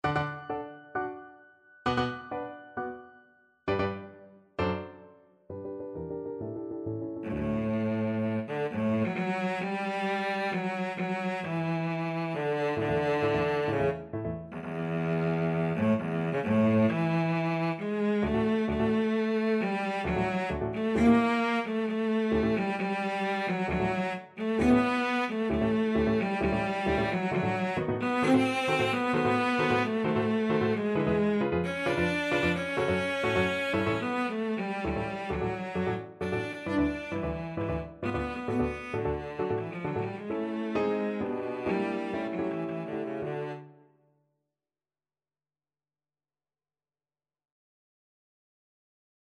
Classical Berlioz, Hector Idee fixe from Symphonie Fantastique Cello version
D major (Sounding Pitch) (View more D major Music for Cello )
Allegro agitato e appassionato assai = 132 (View more music marked Allegro)
Cello  (View more Intermediate Cello Music)
Classical (View more Classical Cello Music)